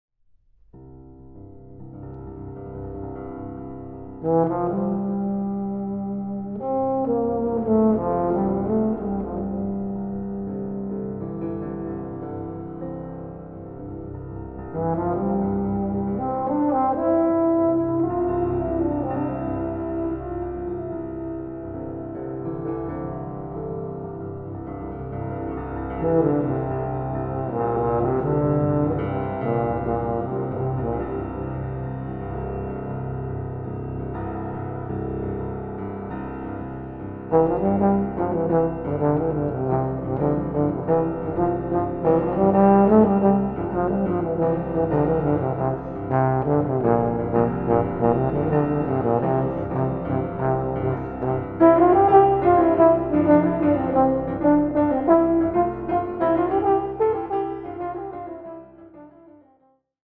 Aufnahme: Mendelssohn-Saal, Gewandhaus Leipzig, 2025
Version for Euphonium and Piano